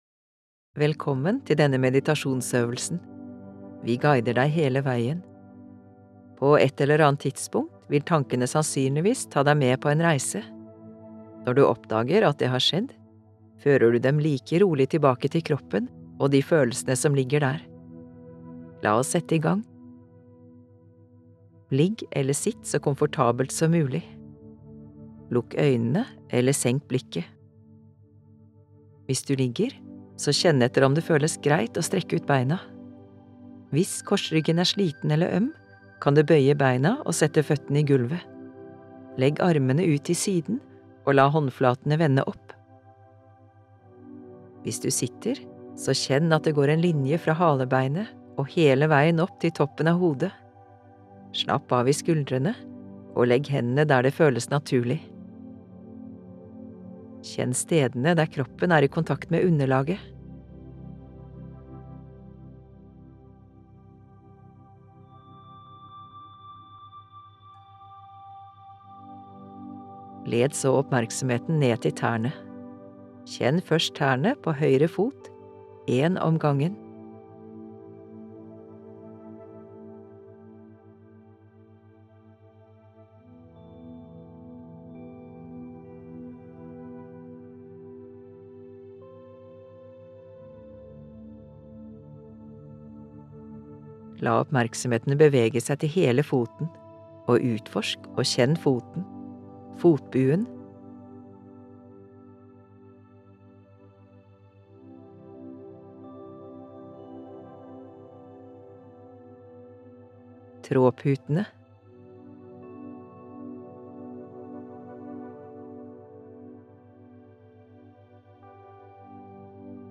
Kroppsskanning – meditasjonsøvelse med lydguide
Her kan du lytte til en guidet meditasjon.